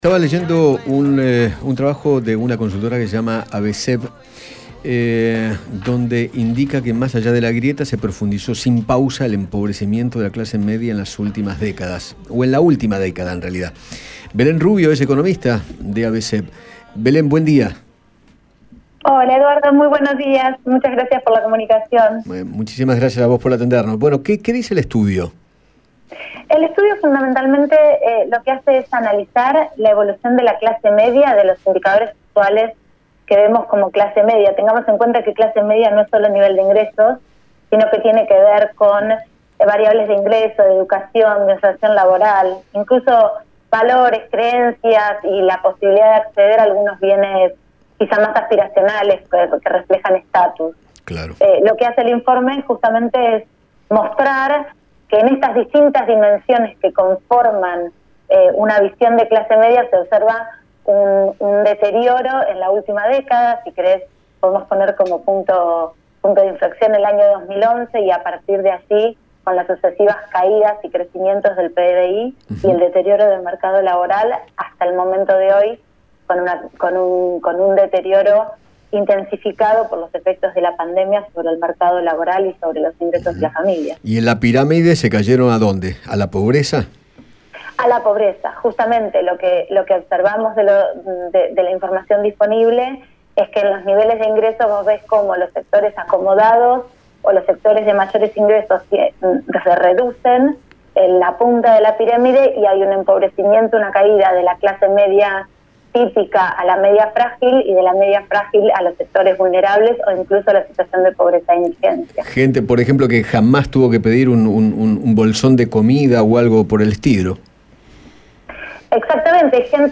dialogó con Eduardo Feinmann sobre el estudio que realizó la consultora acerca de la evolución de la clase media en la última década.